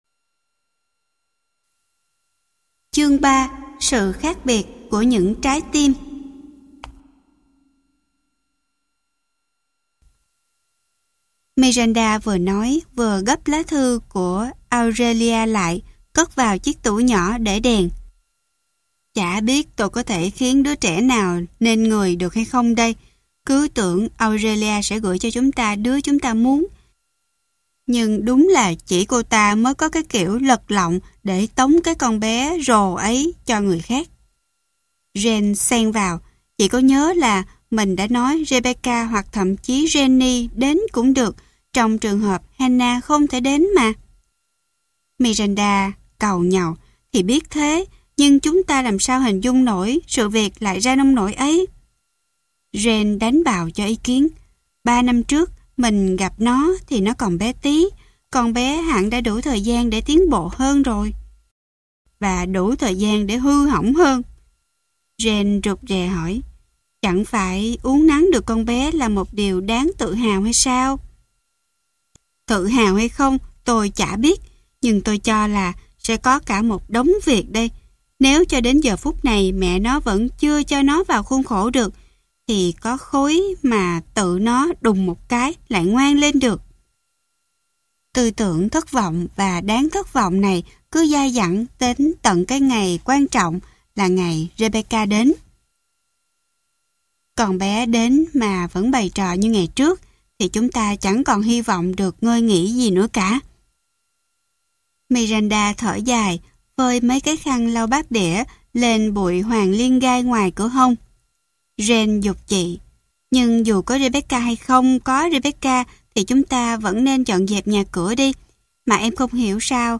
Sách nói Rebecca Ở Trang Trại Suối Nắng - Kate Douglas Wiggin - Sách Nói Online Hay